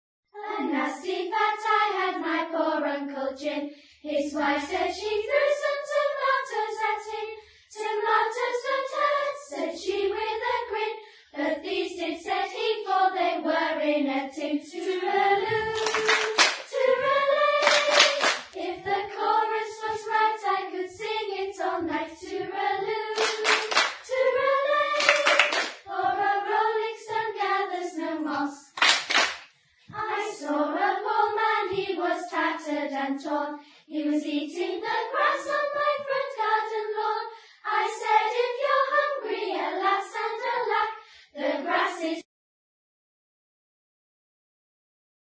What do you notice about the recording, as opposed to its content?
In May 2000, the Various Musical Ensembles of the school recorded a CD of the pieces they would be performing in the May 2000 concert.